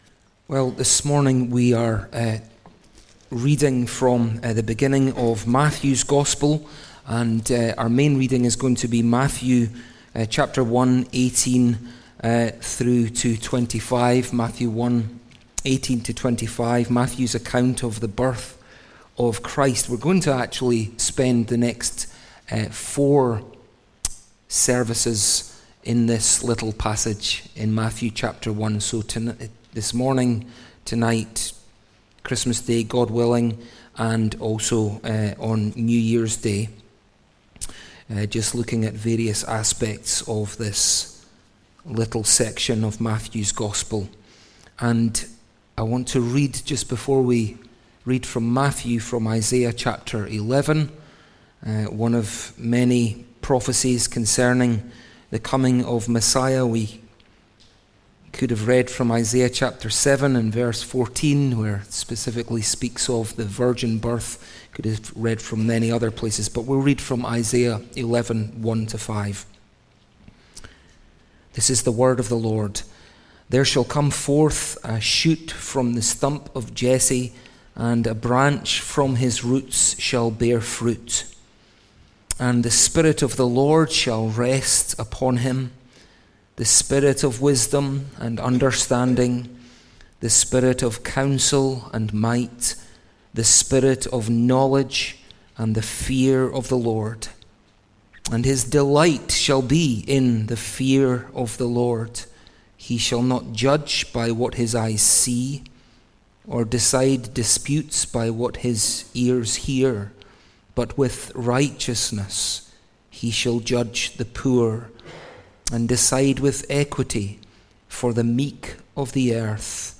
Conceived of the Holy Spirit | SermonAudio Broadcaster is Live View the Live Stream Share this sermon Disabled by adblocker Copy URL Copied!